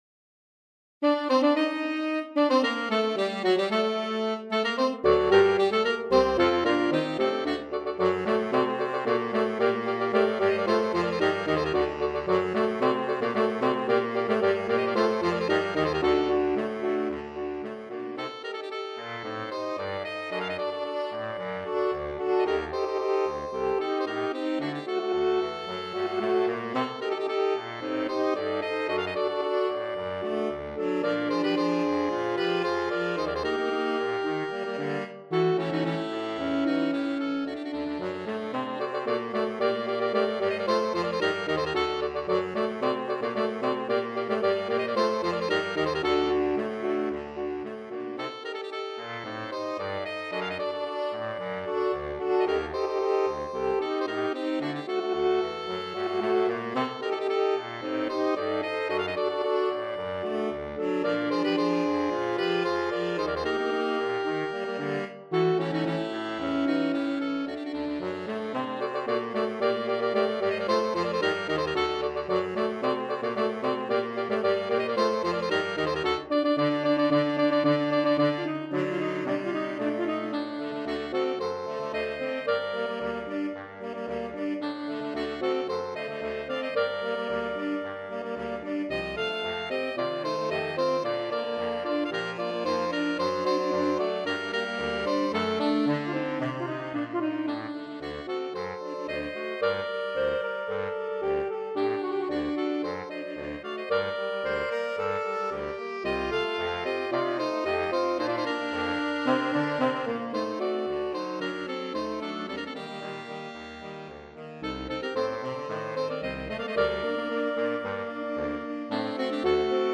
Ensemble: Sax Quartet SATB with alternate parts